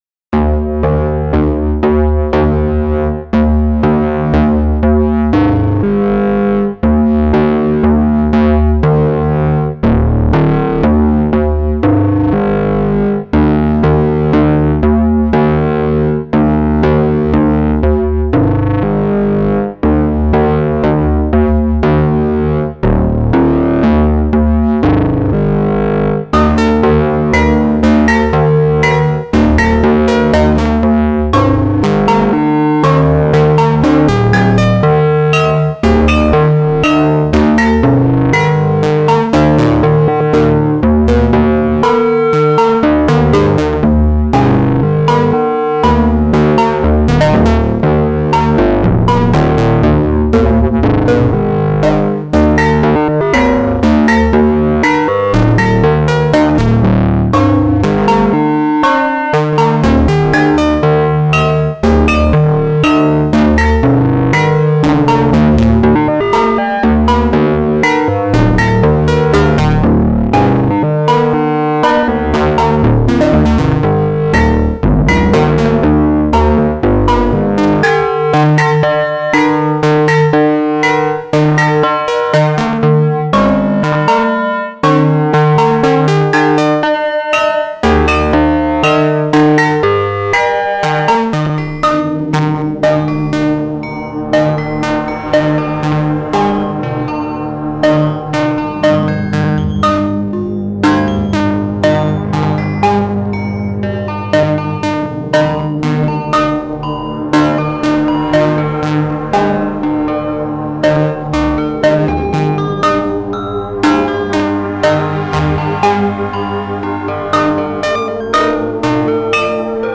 (MP3) (YouTube)   3:09 Ring modulation and white noise are things I don't use as much in my music as some folks do, and I was thinking today I should correct that. So here's a bit of both.
Voice 1: Tides ring-modulated against Rings, through A-102 filter.
Layered with filtered white noise from E352, through Mini-Slew controlled Dynamix.
Voice 3: Pads from PlastiCZ VT through SpecOps and ValhallaVintageVerb.